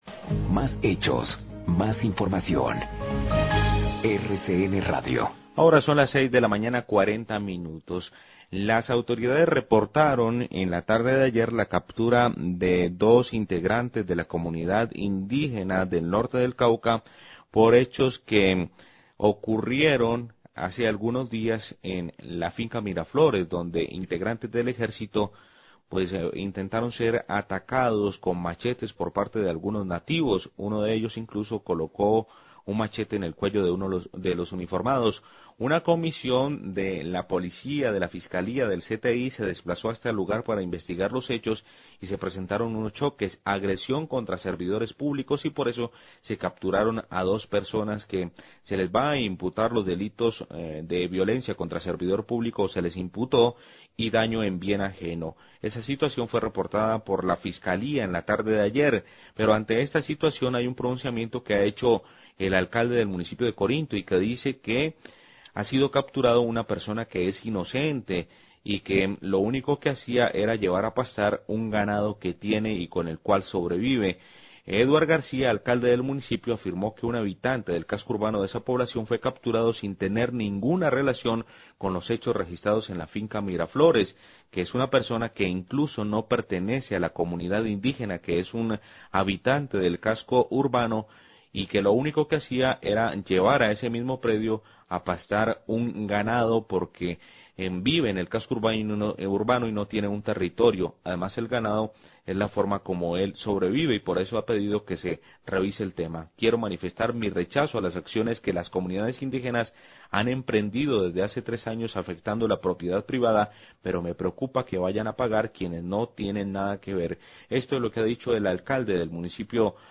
Radio
Captura de 2 integrantes de la comunidad indígena del Norte del Cauca por hechos ocurridos en la hacienda Miraflores donde integrantes del Ejército fueron atacados, se imputarán los delitos de violencia contra servidor público y daño en bien ajeno. Declaraciones del Alcalde de Corinto, Eduard García.